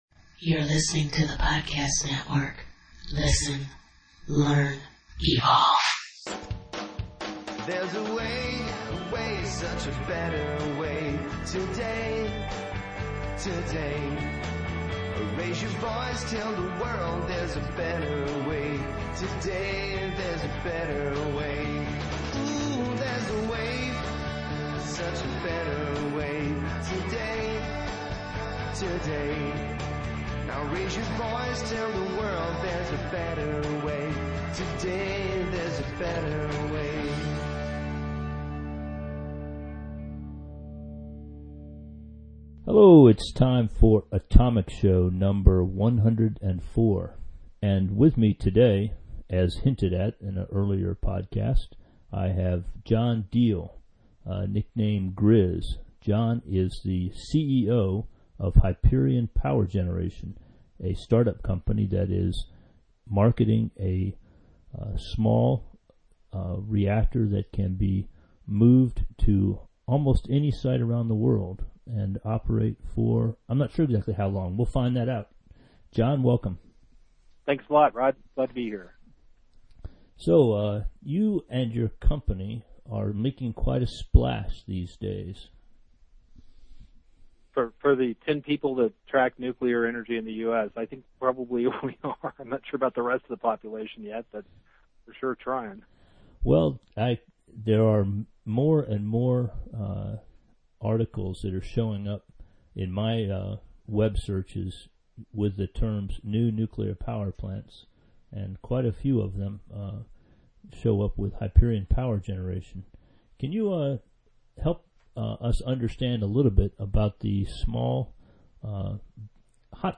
The Hyperion Power Module is described by company literature as being about the size of a deep hot tub and will produce approximately 70 MW of thermal power. As you will learn if you listen to the interview, the company expects that the cost per unit heat once the systems are in production will be approximately $3 per million BTU – anywhere in the world.